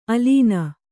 ♪ alīna